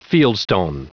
Prononciation du mot fieldstone en anglais (fichier audio)
Prononciation du mot : fieldstone